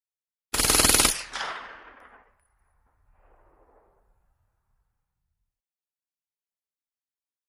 9 mm UZI Automatic: Single Burst with Echo; 9 mm UZI Automatic Fires A Single Burst Of Shots With Echo, Clank Of Shell Falling Onto Hard Ground. Medium Perspective. Gunshots.